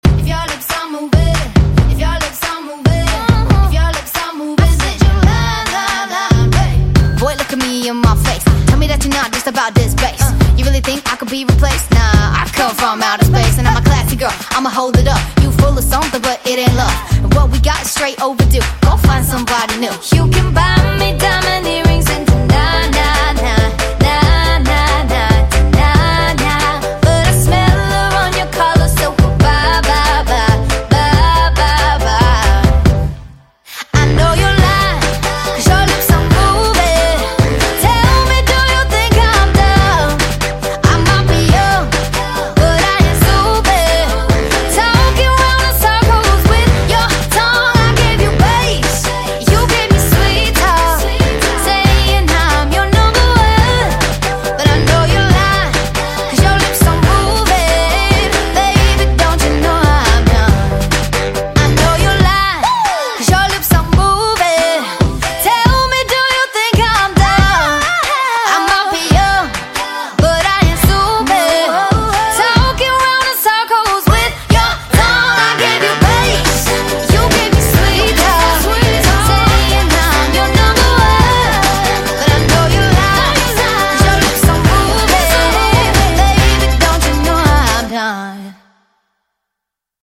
BPM139
MP3 QualityMusic Cut
CommentsThe modern pop.